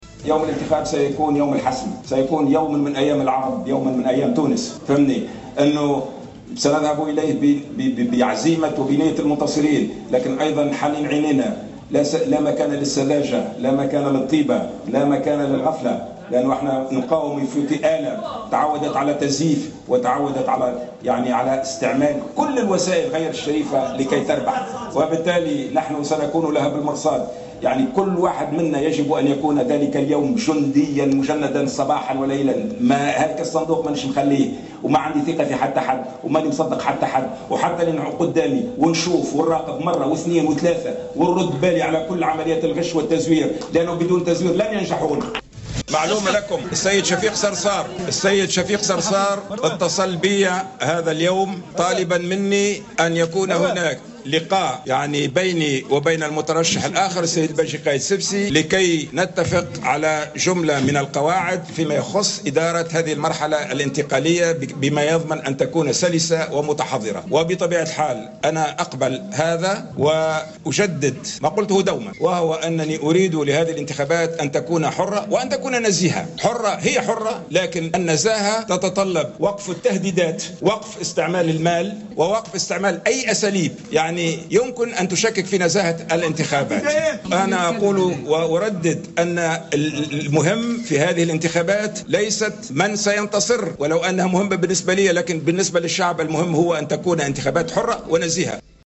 أكد المترشح للدور الثاني من الانتخابات الرئاسية محمد المنصف المرزوقي، في افتتاح حملته الانتخابية اليوم الثلاثاء، أن يوم الانتخاب هو يوم الحسم سيكون يوما من أيام تونس لا مجال فيه للغفلة و السذاجة على حد تعبيره.